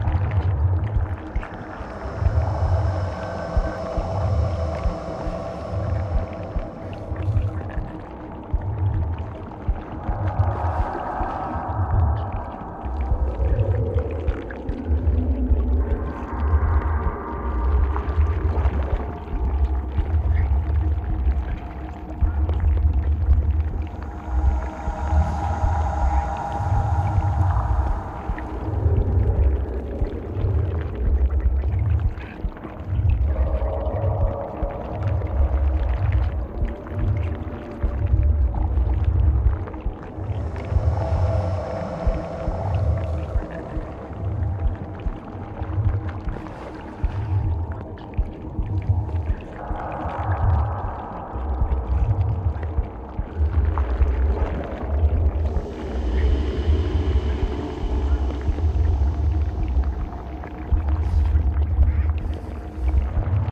Sfx_amb_treespire_ventgarden_inner_amb_01.ogg